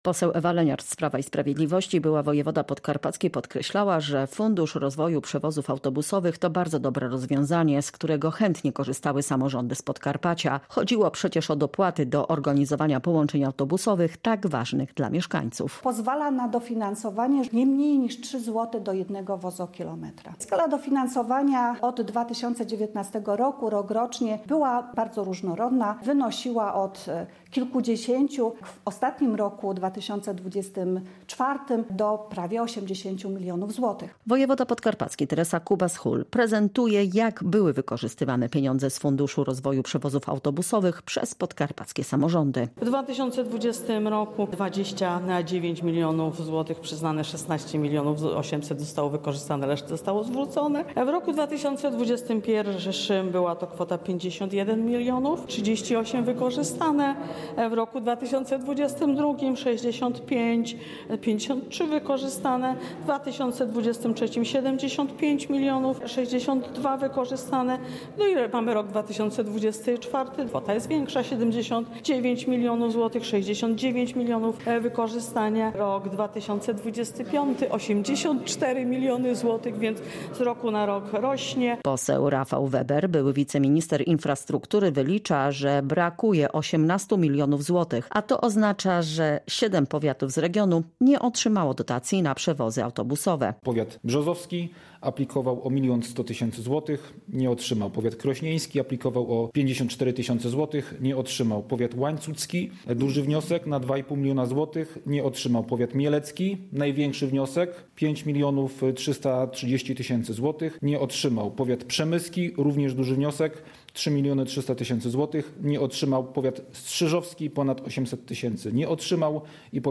Siedem powiatów wciąż bez pieniędzy • Relacje reporterskie • Polskie Radio Rzeszów